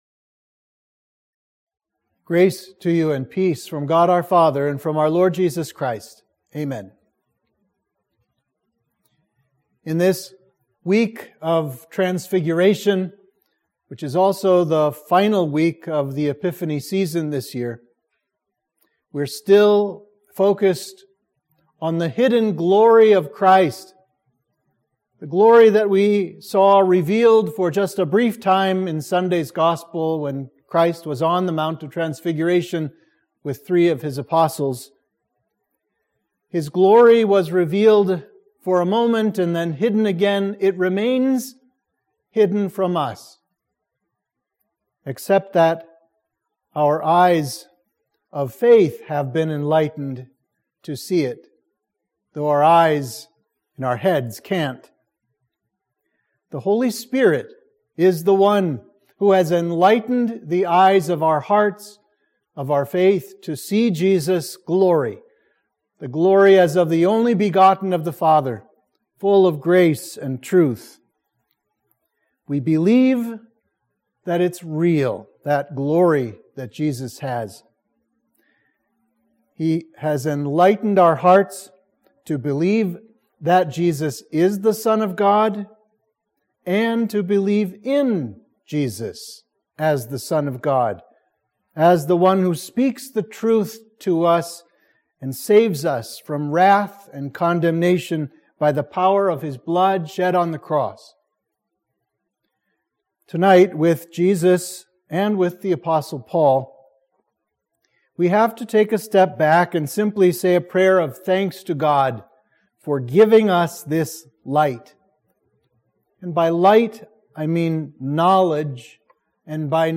Sermon for midweek of Transfiguration